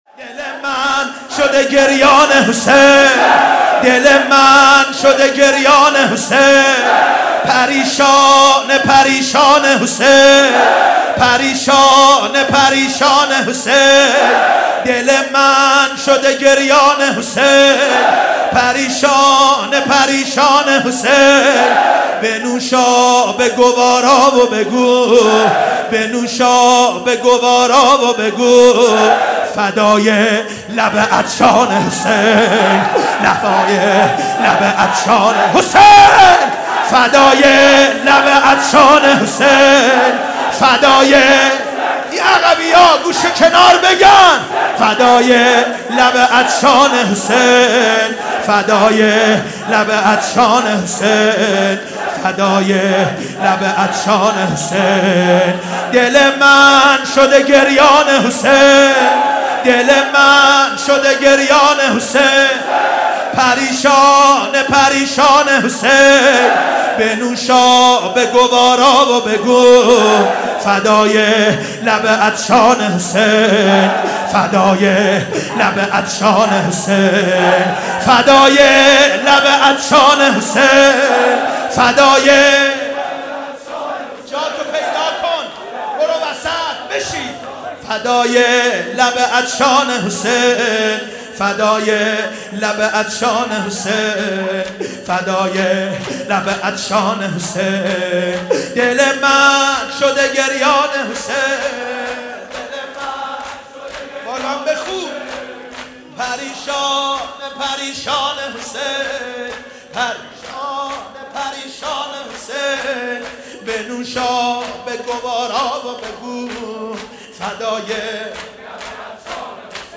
محرم 94